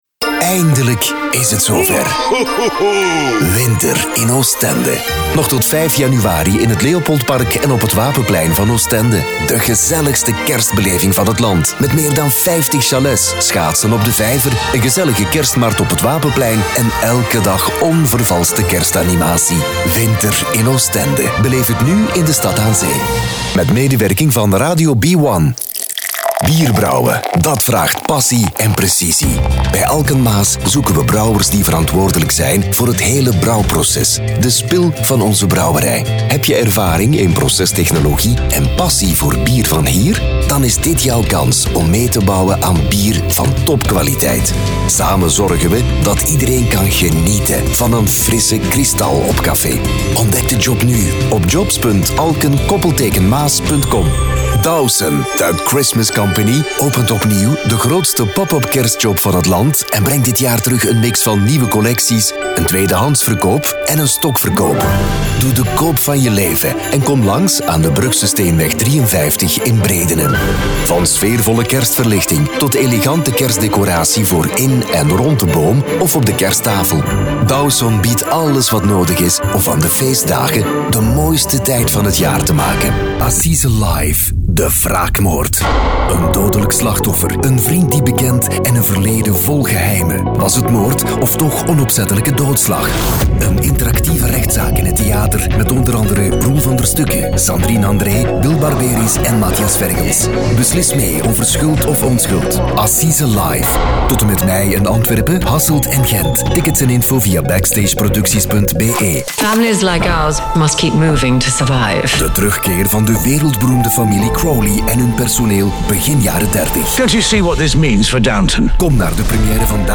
Warm, Diep, Vertrouwd, Volwassen, Toegankelijk
Commercieel